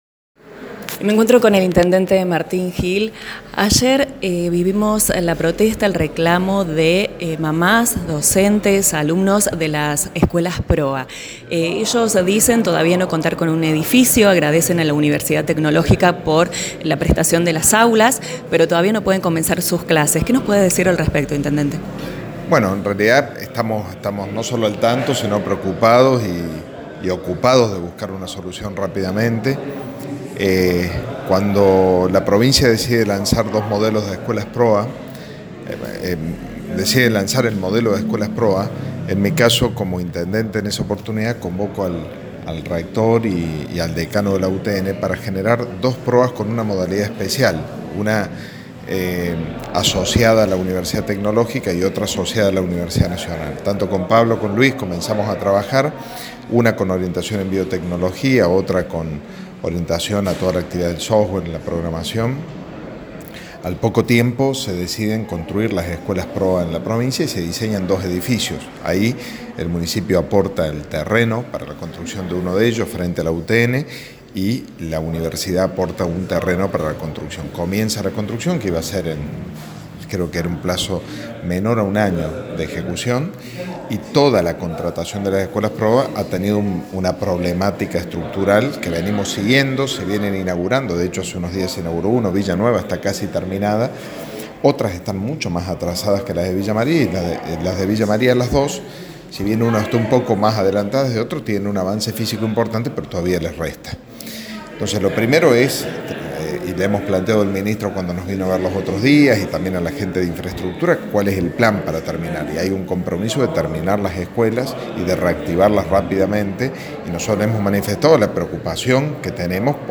AUDIO – INT. MARTÍN GILL
El Intendente Martín Gill se refirió este jueves en Radio Centro a los reclamos que se conocieron ayer desde las Escuelas PROA, tanto de docentes, padres y alumnos, preocupados por los retrasos de la construcción de los edificios, el nombramiento de docentes y la falta de dictado de clases o de la totalidad de las horas cátedra que deben dictarse.